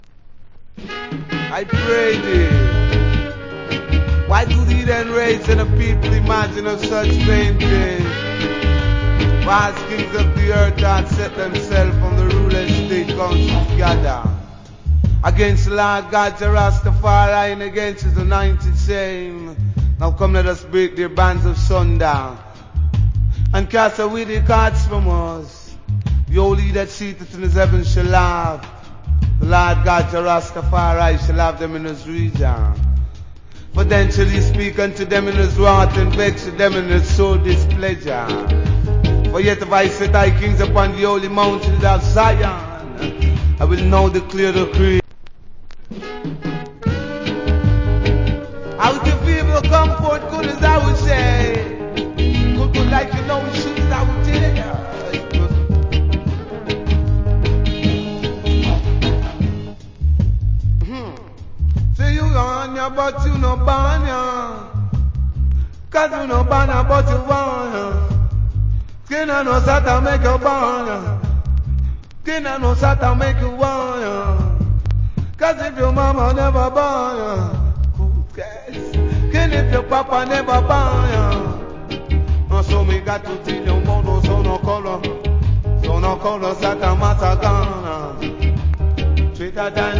Good DJ.